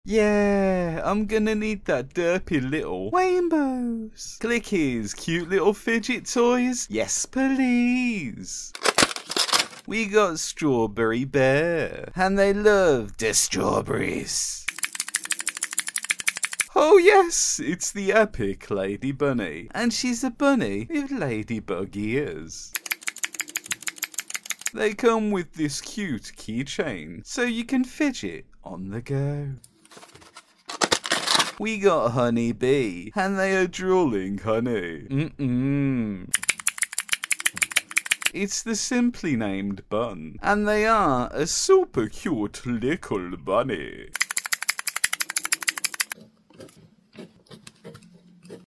[AD] Clickeez Cute Clickable Keyboard sound effects free download
[AD] Clickeez Cute Clickable Keyboard Keys ASMR Fidget Toy Unboxing